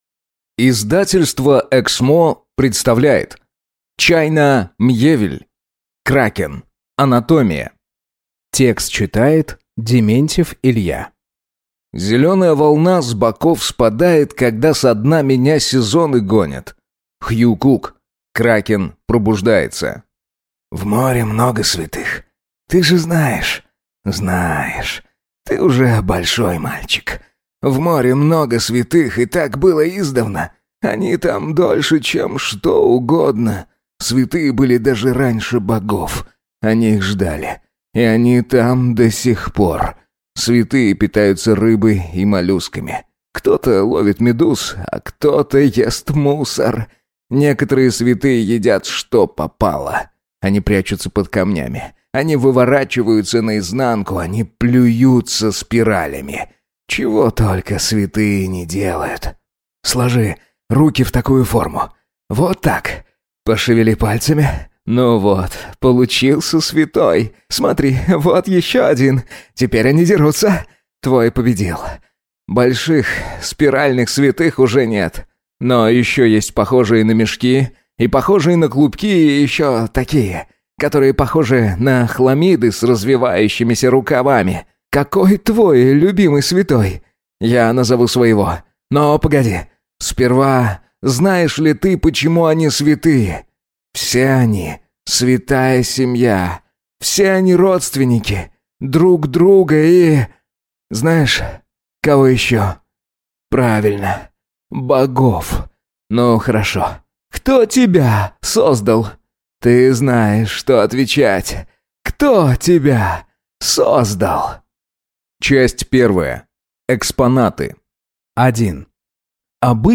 Аудиокнига Кракен | Библиотека аудиокниг
Прослушать и бесплатно скачать фрагмент аудиокниги